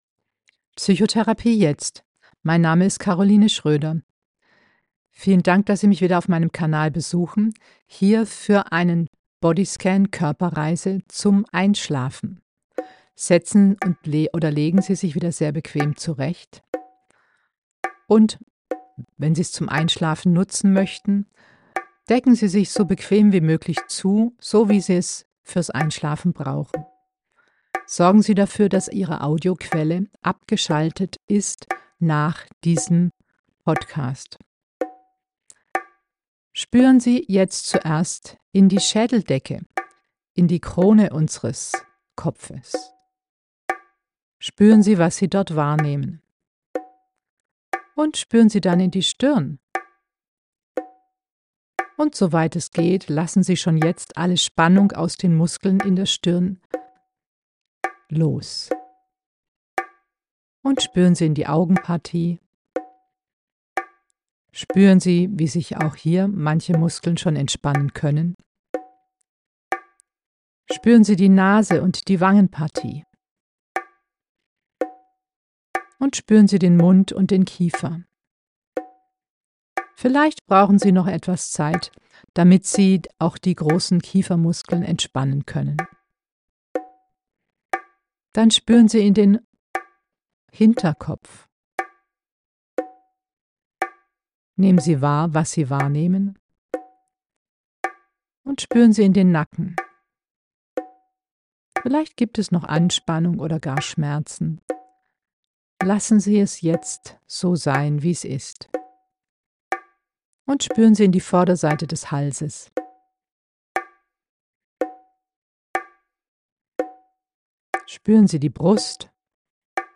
die akustischen Signale (Klavier oder Bongo) nicht ganz im Rhythmus